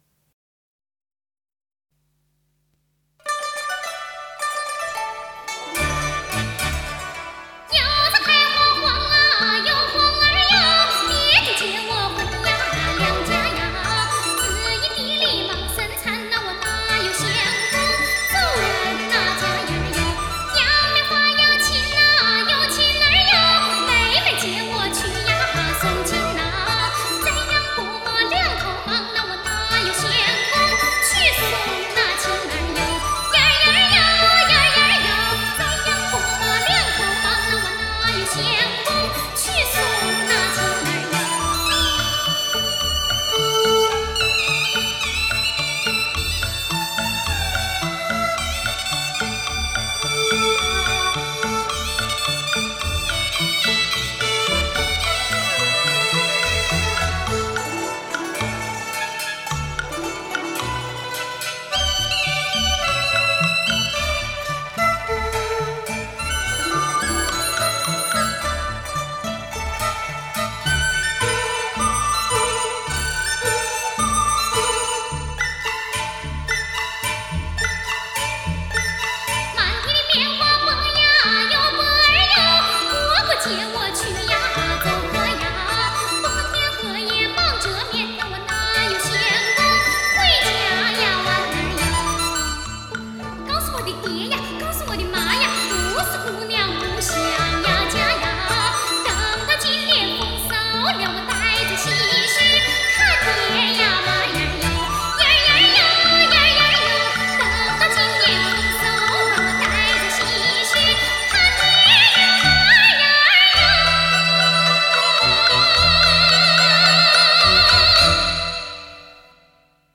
广西民歌